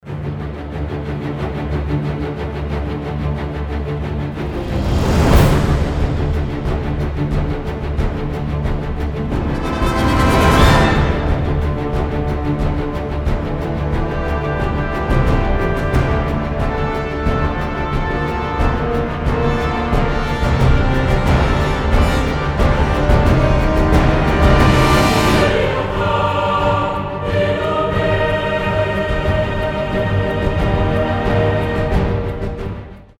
• Качество: 320, Stereo
хор
эпичные
боевые